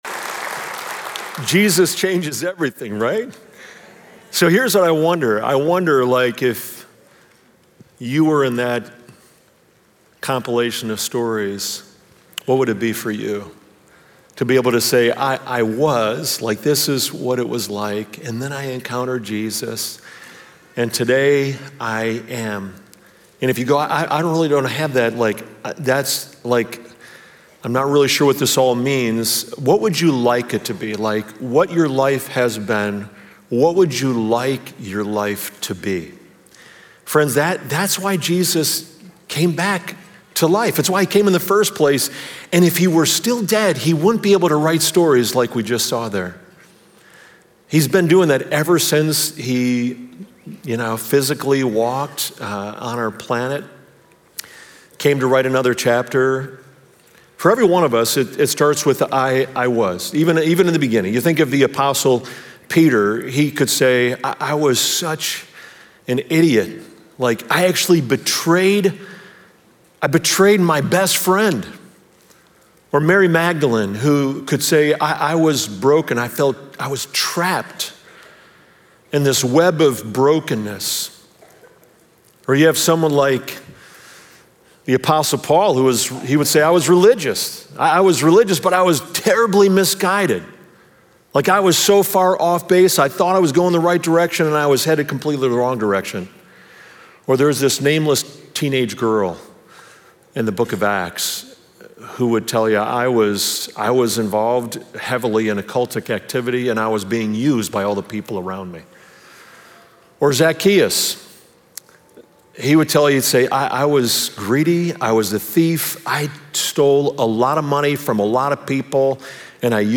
In this Easter message